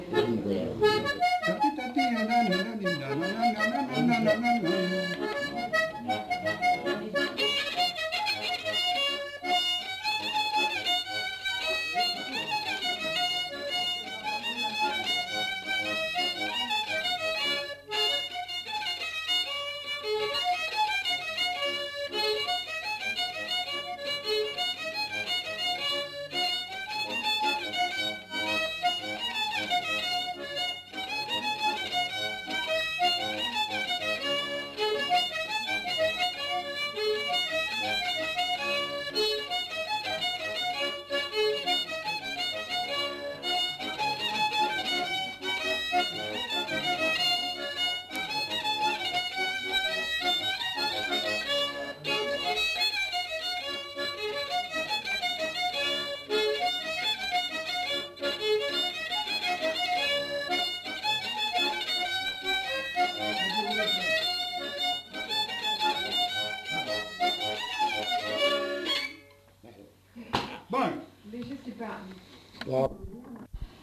Rondeau